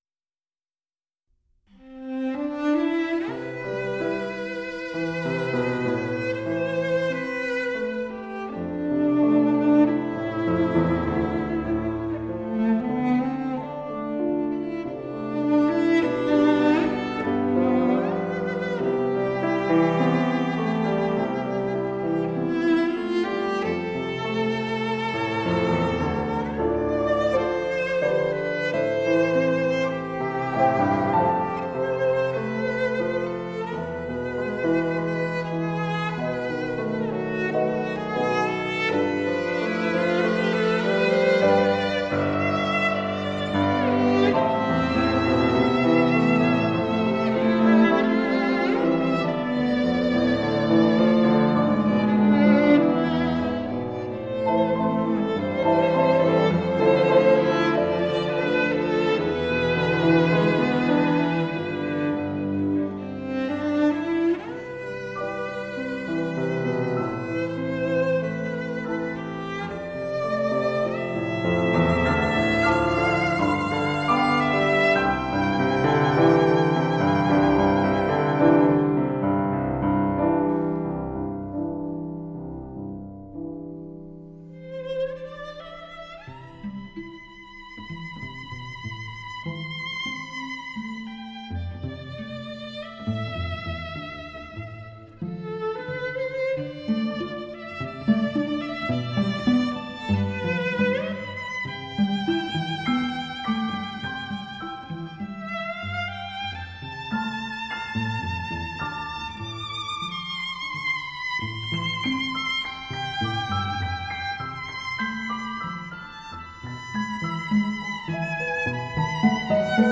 这首乐曲委婉动人，如温柔的手指划过心房，像凝望少女眼中百转千回的情愫，穿越时空久久萦绕……